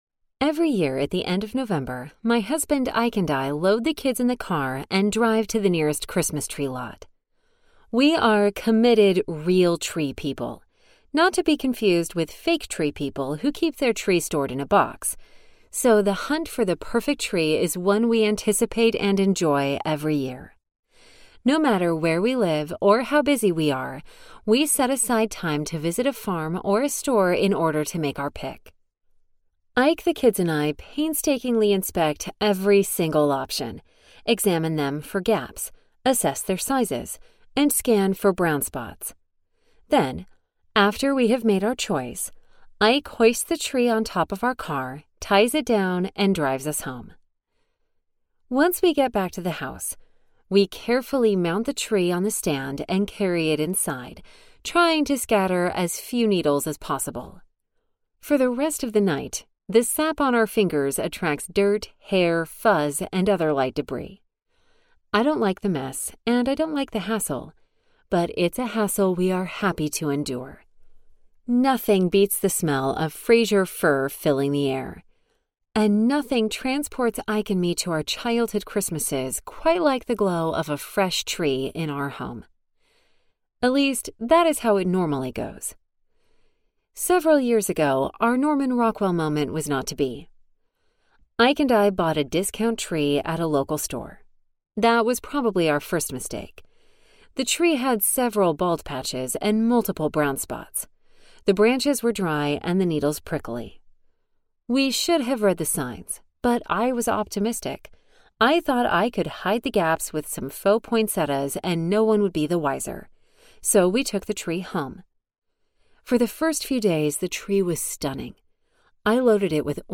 Nice Audiobook
5.9 Hrs. – Unabridged